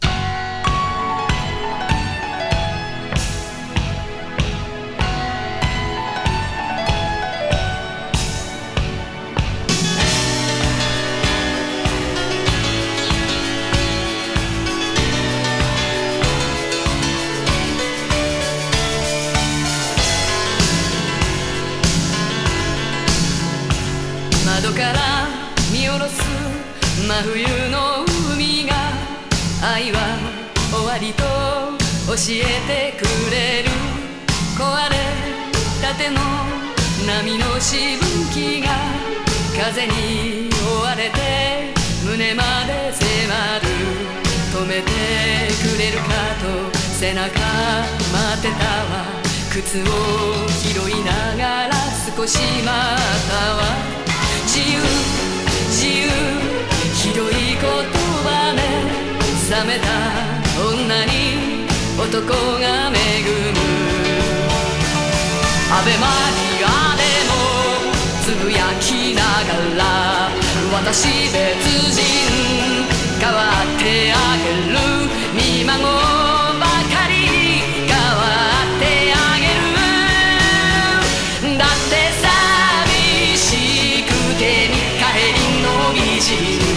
ミッドテンポのロッキン・ダンサブル・ナンバー！